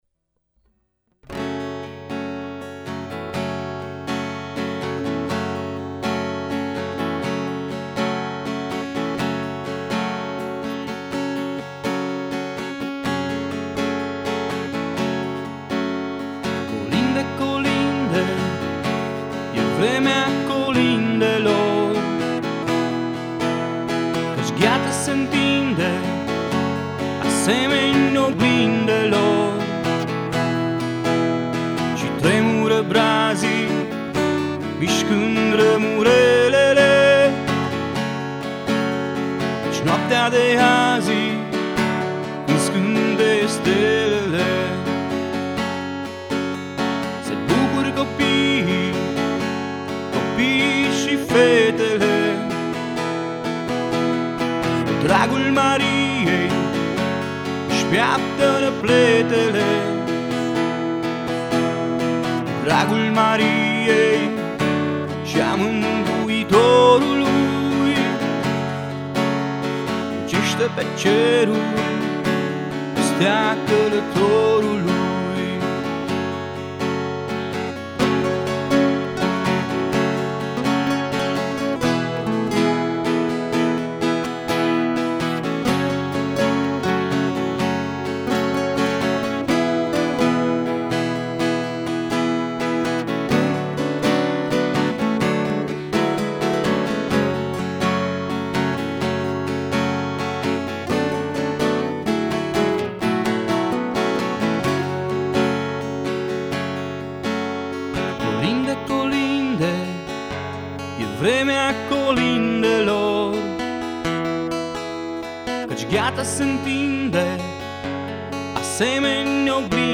recital de colinde